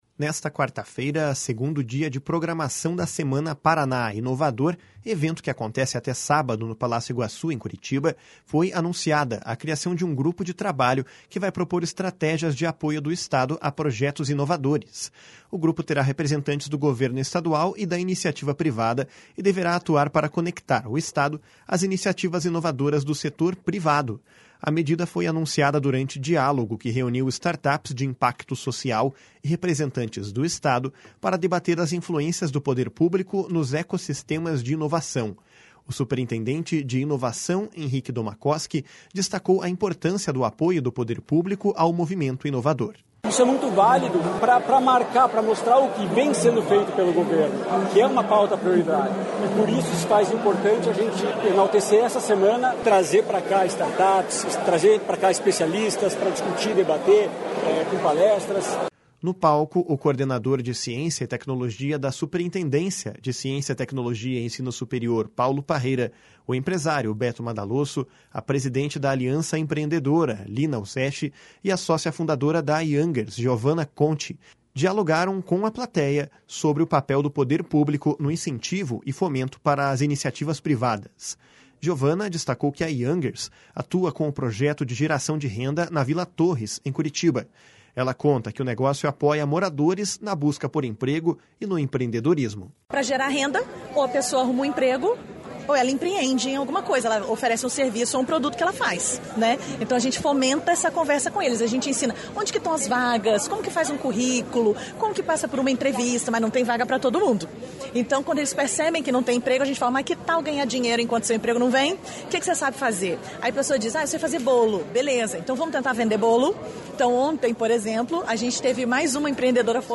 O superintendente de Inovação, Henrique Domakoski, destacou a importância do apoio do poder público ao movimento inovador. // SONORA HENRIQUE DOMAKOSKI //